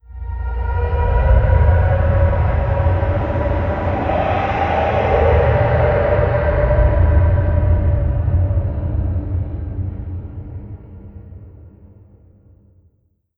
c_aguard_hit2.wav